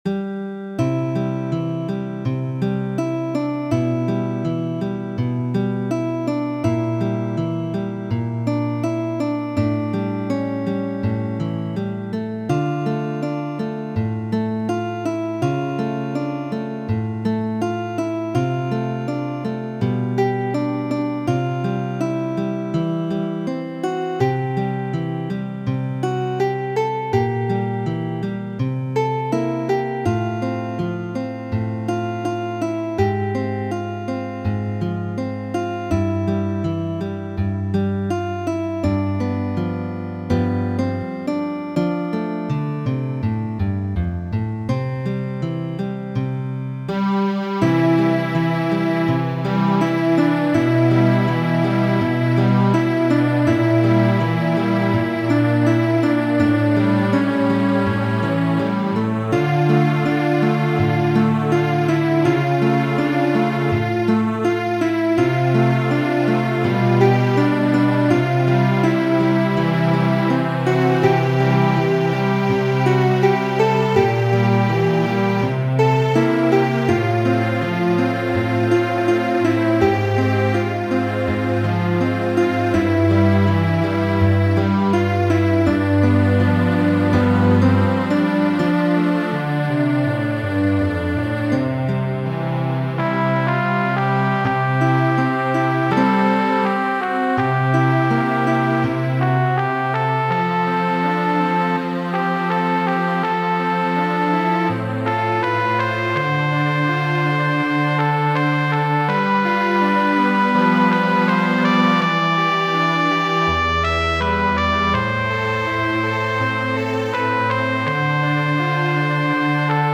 gitarigita